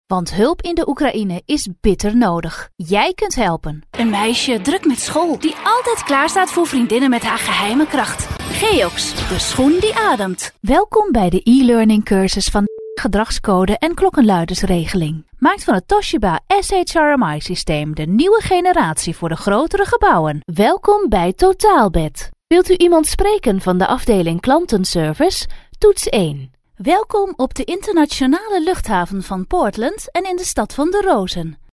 Female
Adult (30-50)
Warm energetic reliable friendly female voice with over ten years experience.
Main Demo